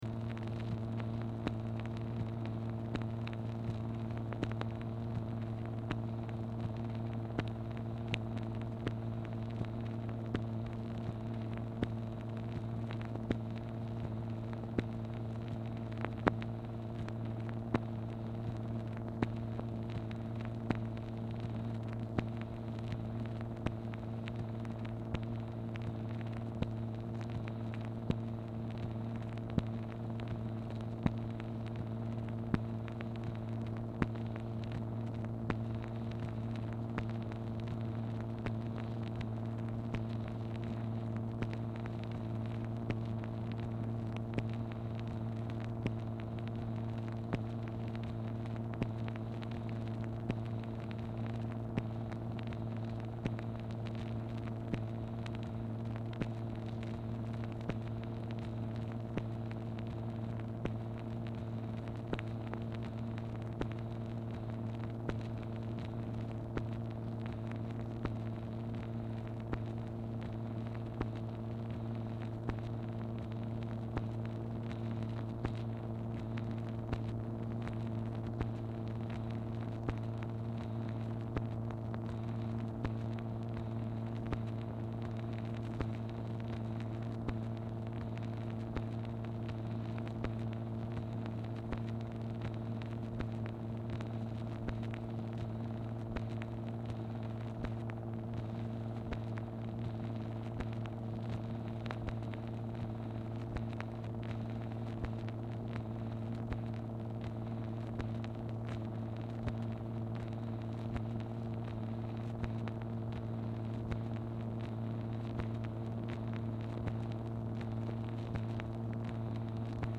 Telephone conversation # 3135, sound recording, MACHINE NOISE, 4/25/1964, time unknown | Discover LBJ
Format Dictation belt
Specific Item Type Telephone conversation